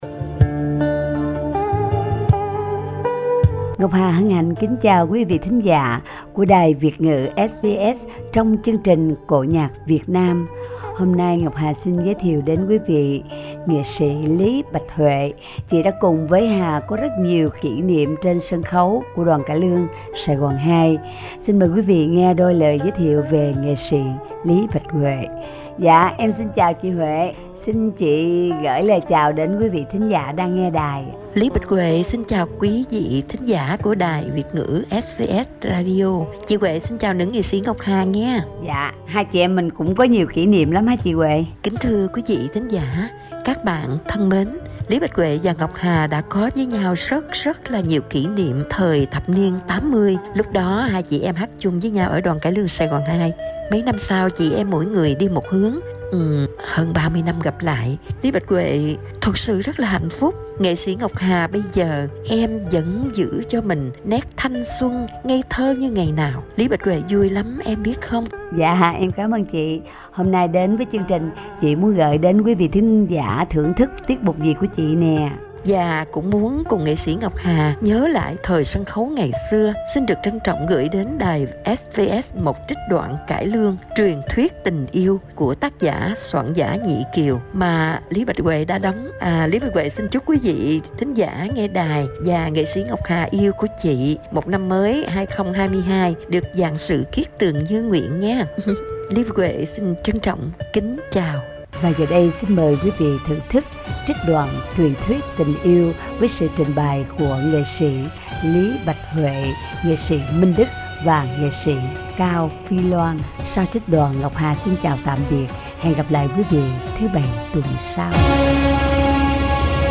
Giọng ca Vọng cổ
trích đoạn cải lương